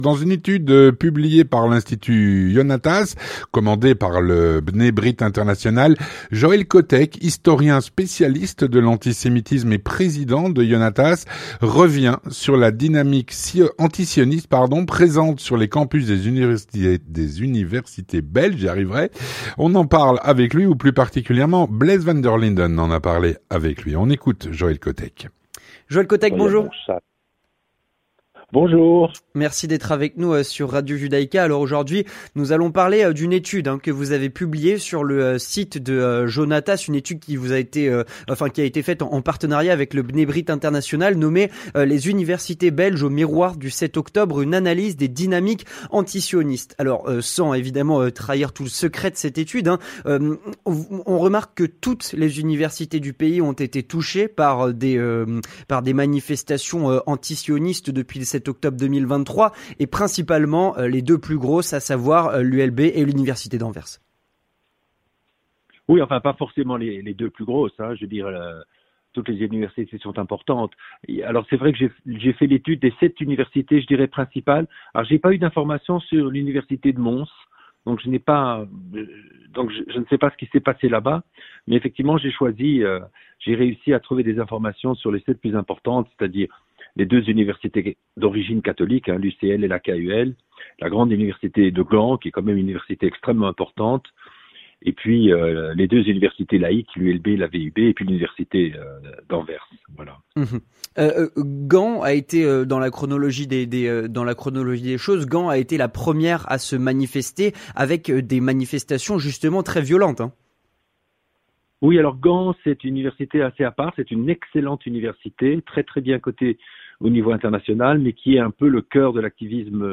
L'entretien du 18H - La dynamique antisioniste présente sur les campus des universités belges.